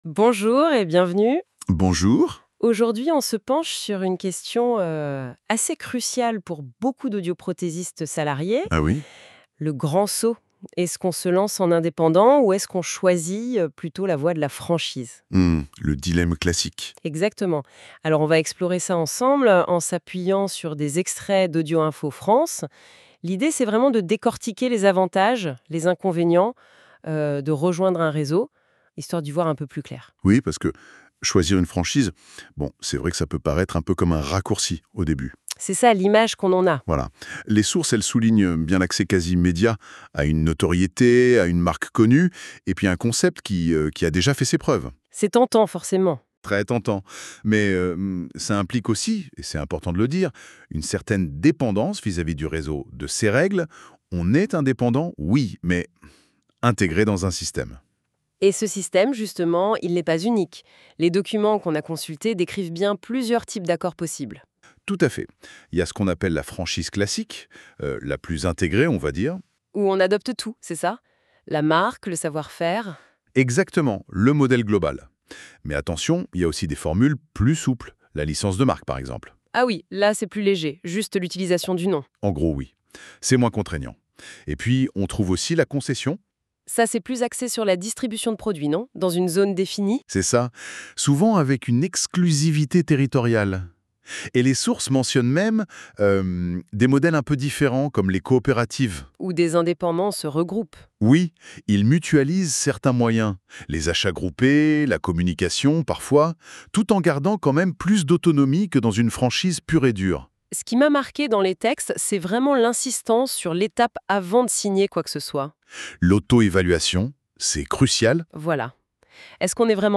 Les personnages intervenant dans cet épisode sont fictifs. L’utilisation de l’IA a été fait ici dans le but de fournir un résumé oral de l’article précité.